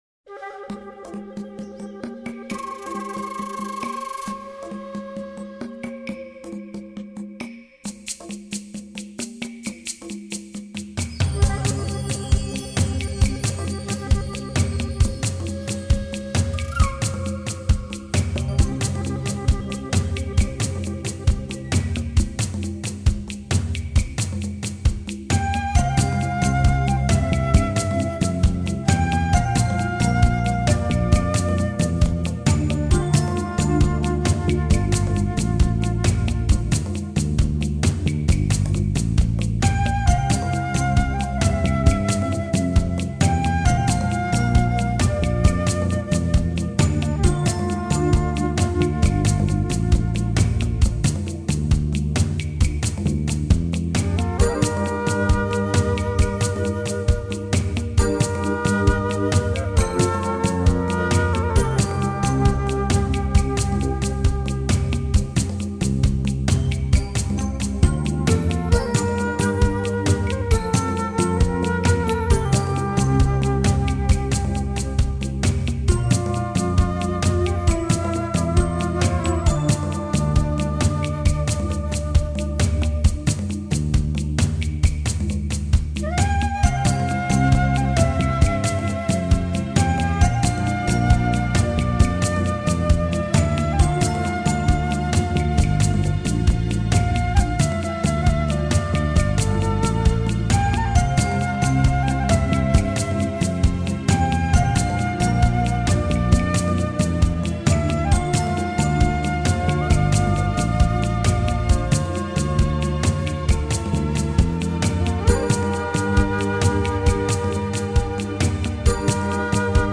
相对于其他专辑来说更富有独特的笛声和节奏